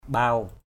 /ɓaʊ/